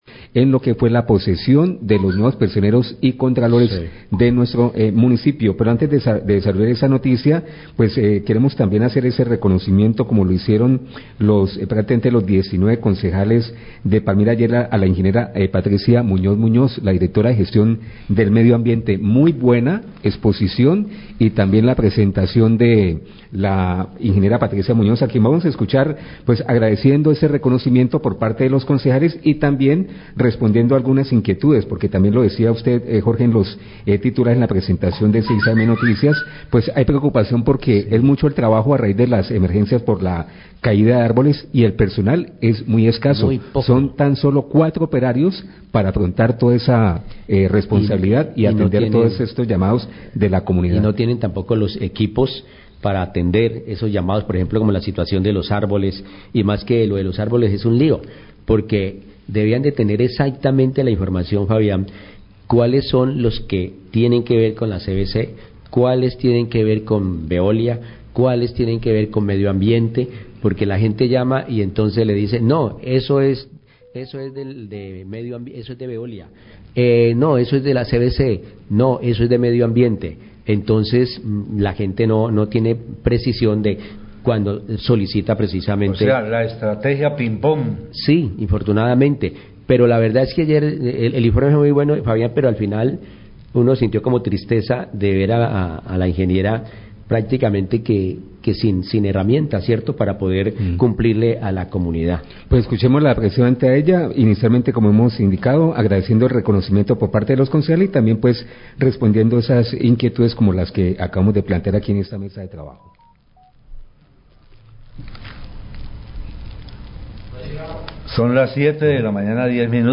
Radio
Periodistas hablan de la intervención de la Secretaria Gestión del Riesgo y Desarrollo Sostenible de Palmira, ing. Patricia Munóz, sobre la dificil situación de las fuertes lluvias y la cáida de árboles.